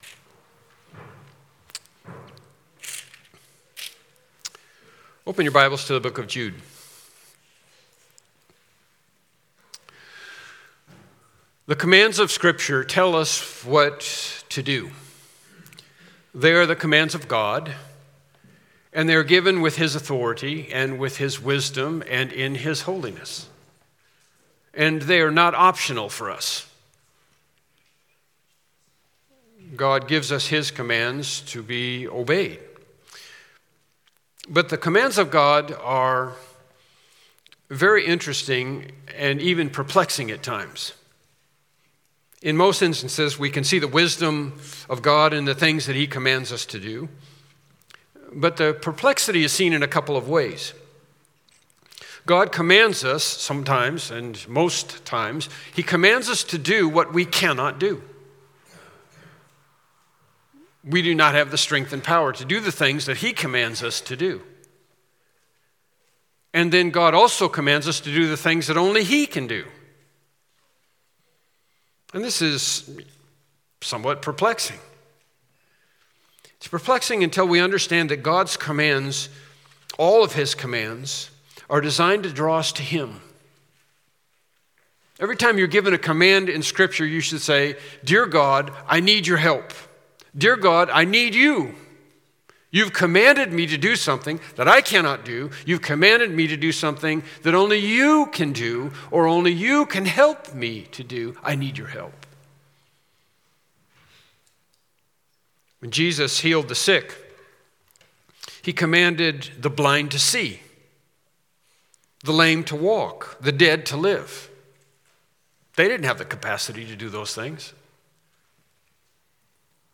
“Keep Yourselves” – HIllcrest Bible Church